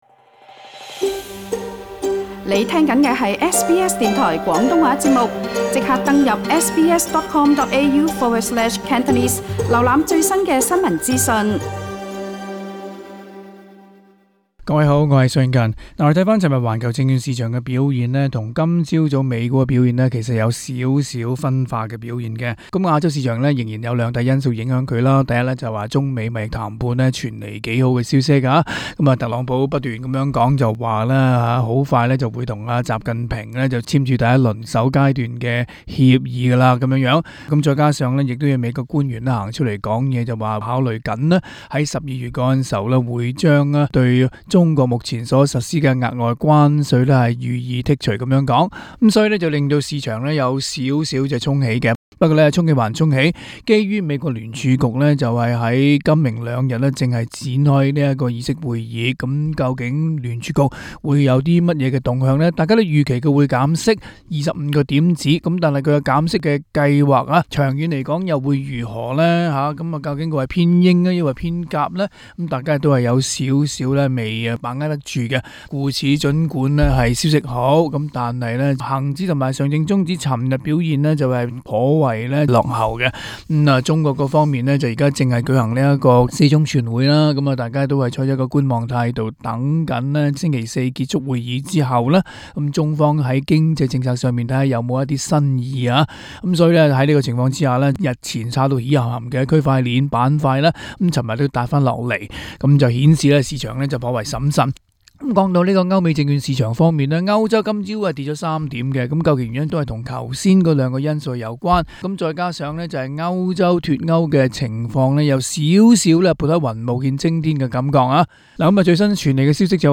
Source: Bloomberg SBS廣東話節目 View Podcast Series Follow and Subscribe Apple Podcasts YouTube Spotify Download (17.46MB) Download the SBS Audio app Available on iOS and Android 10月29日週二， 環球證券市場表現分化。 中美貿易談判及美國聯儲局開會在即，這兩個消息繼續左右市場，期間亦傳來不同的聲音，導致投資者風險胃納明顯減弱，未敢輕舉妄動。